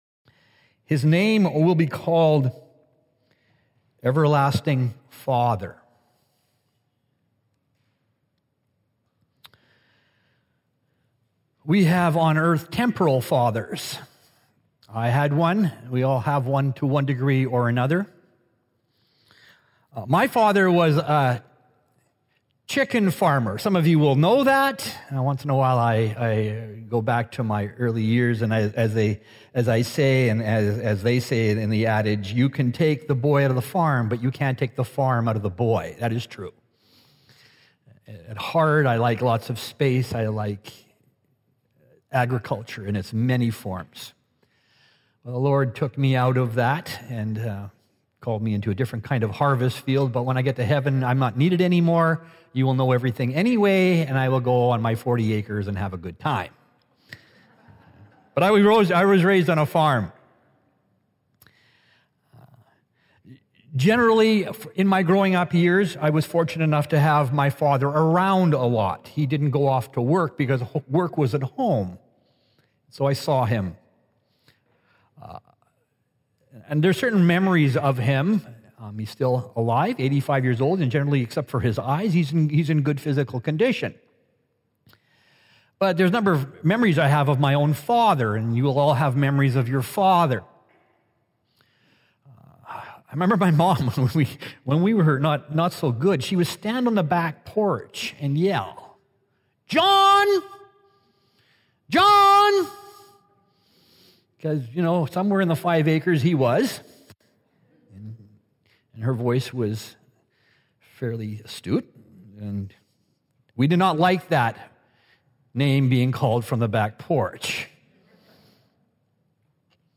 Sermons | Richmond Alliance Church